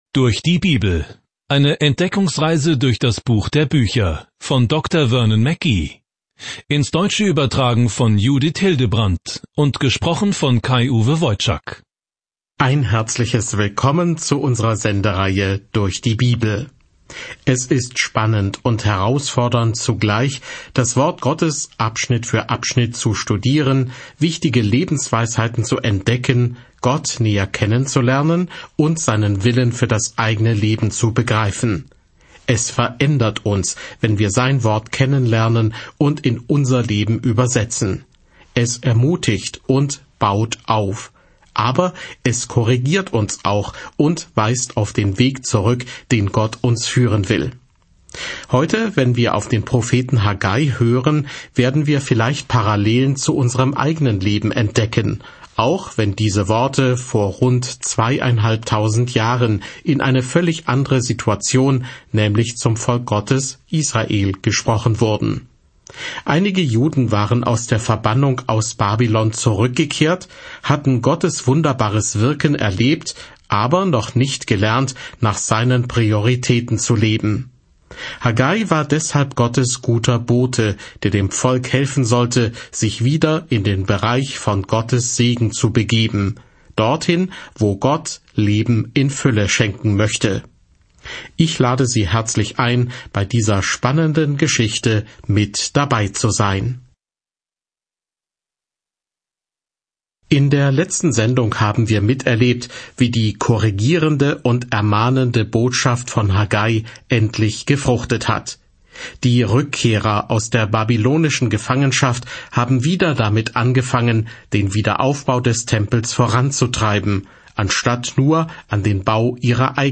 Die Heilige Schrift Haggai 2:1-4 Tag 4 Diesen Leseplan beginnen Tag 6 Über diesen Leseplan Haggais „Erledige es“-Haltung drängt ein zerstreutes Israel dazu, den Tempel wieder aufzubauen, nachdem es aus der Gefangenschaft zurückgekehrt ist. Reisen Sie täglich durch Haggai, während Sie sich die Audiostudie anhören und ausgewählte Verse aus Gottes Wort lesen.